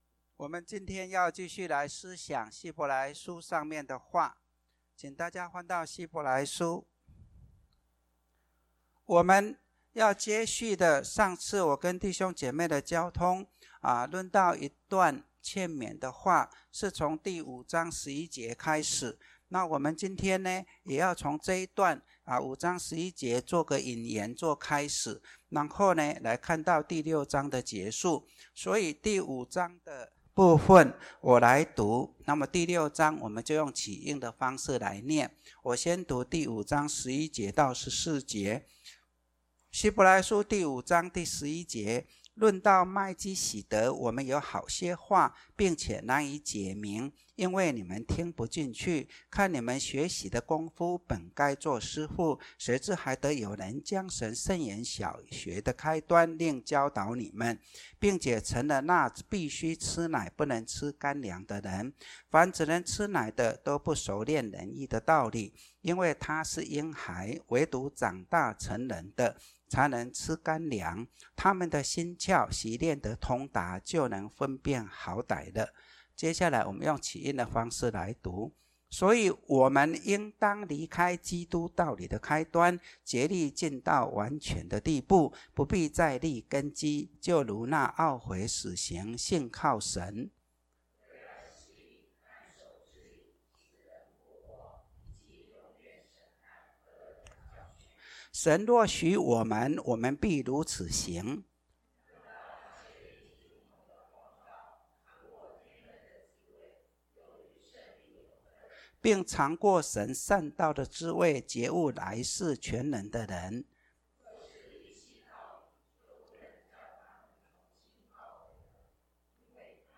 Posted in 主日信息